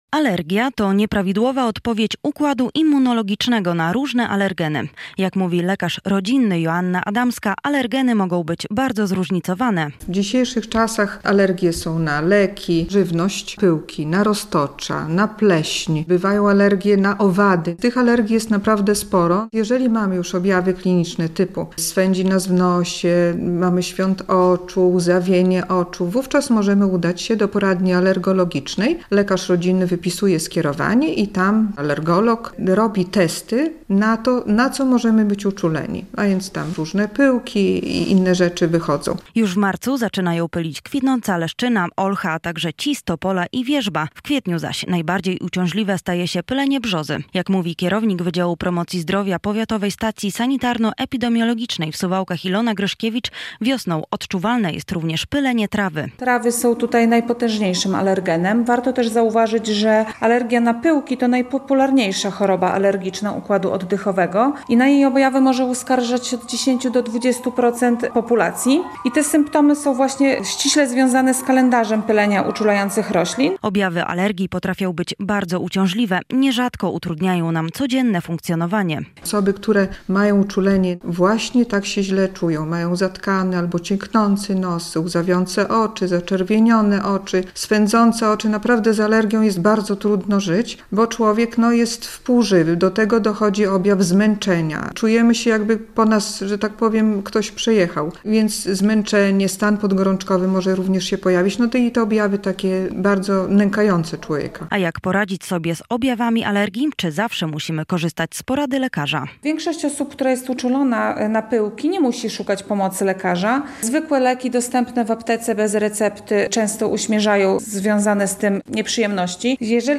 Co może powodować przeciągający się nieżyt nosa w pierwsze cieplejsze dni? - o tym w audycji Zdrowie w słowie.